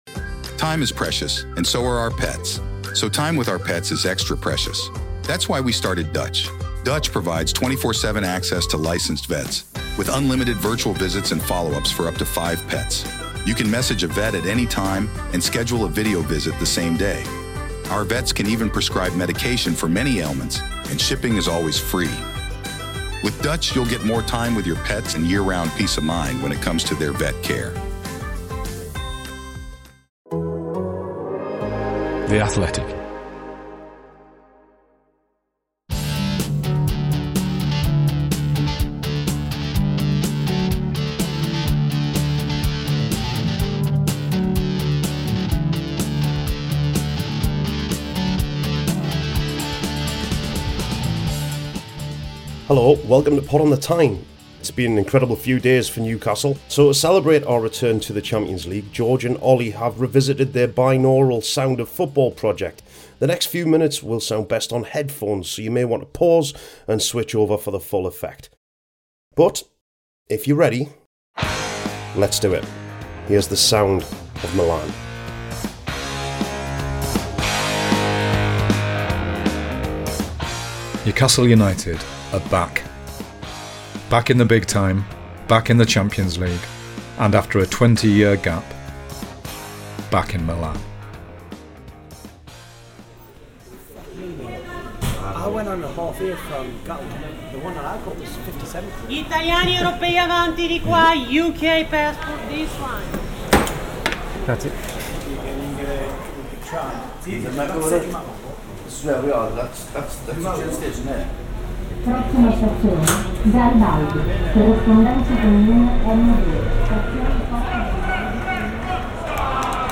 Newcastle returned to the Champions League and they returned to Milan. Get your headphones on for the full stereo effect